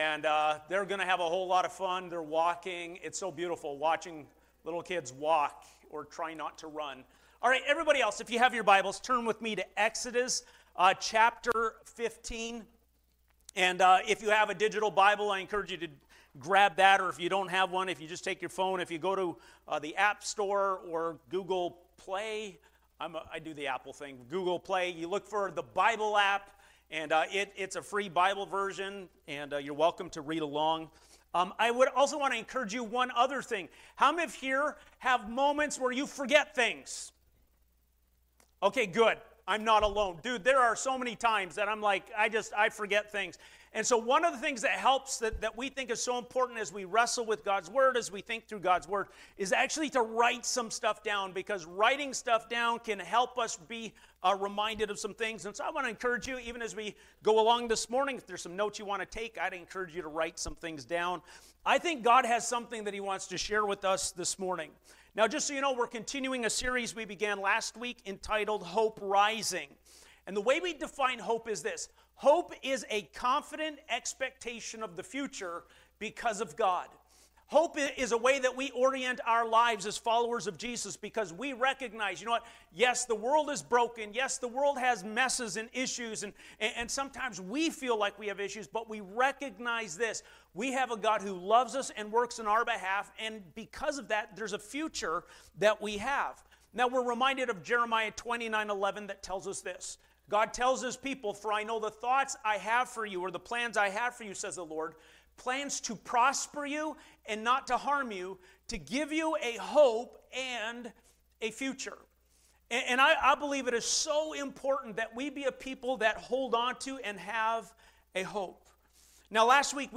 Sermons | Asbury Church